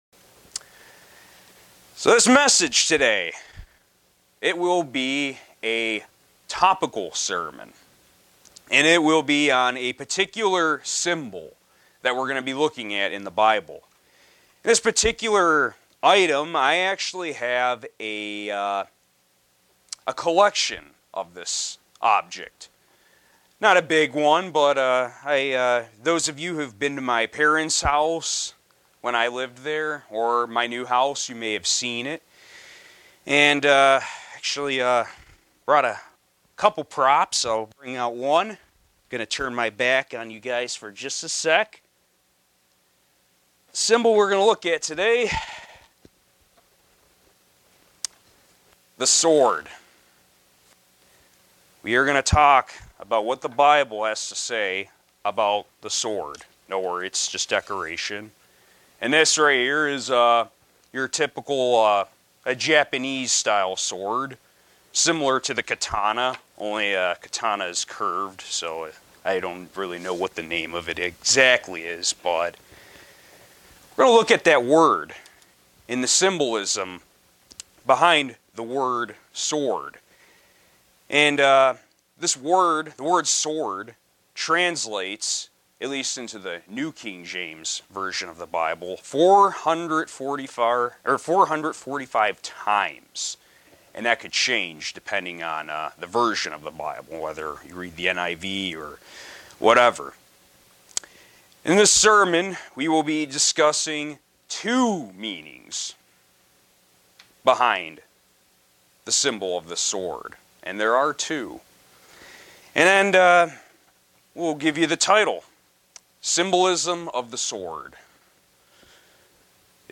Sermons
Given in Buffalo, NY